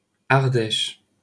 Ardèche (fransuzcha talaffuzi: [aʁdɛʃ] (